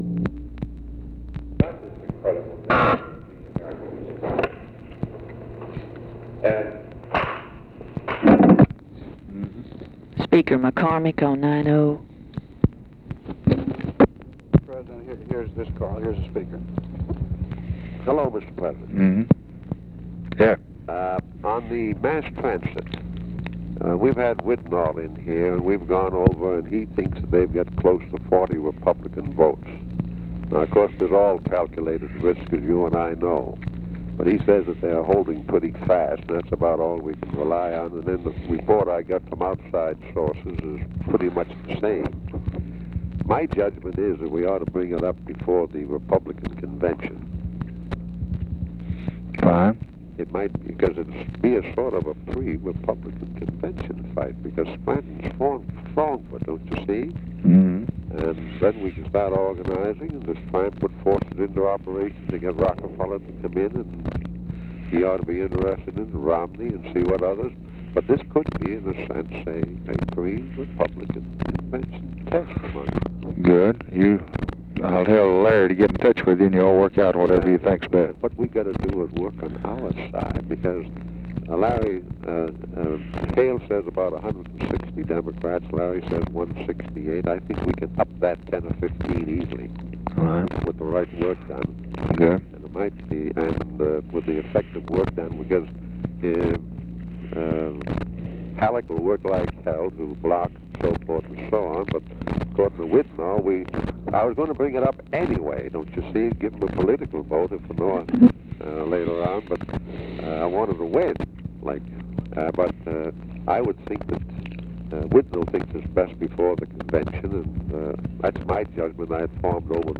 Conversation with JOHN MCCORMACK and CARL ALBERT, June 16, 1964
Secret White House Tapes